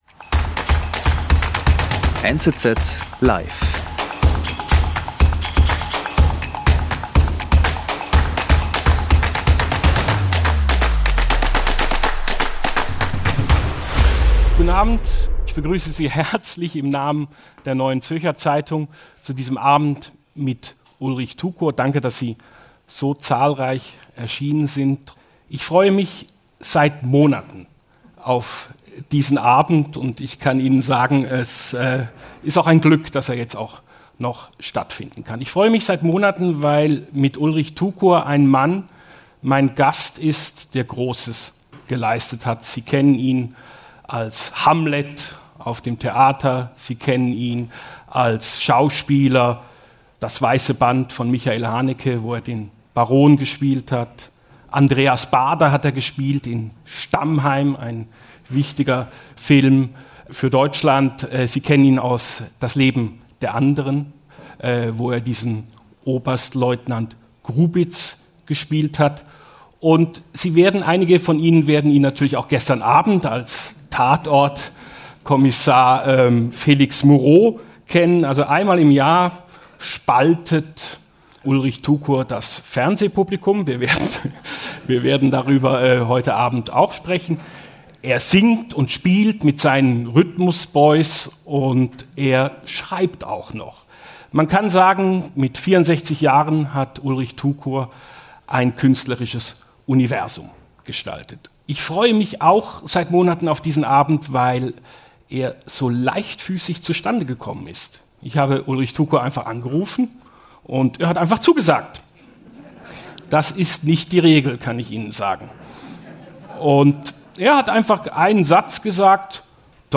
Ulrich Tukur spricht, singt und spielt ~ NZZ Live Podcast
Und zwischendurch spielt und singt er.